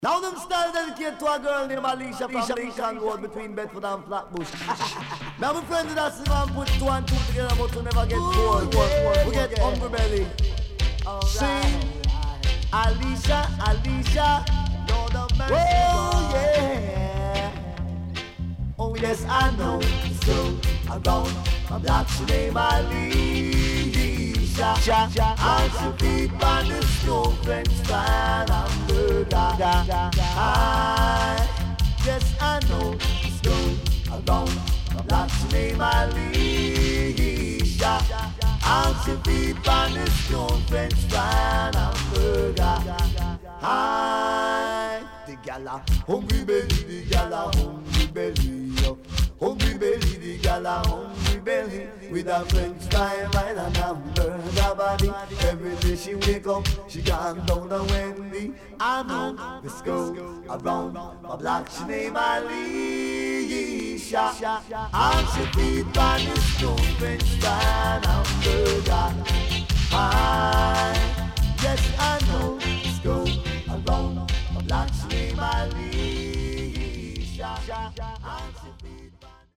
HOME > LP [DANCEHALL]  >  EARLY 80’s
SIDE A:少しノイズ入りますが良好です。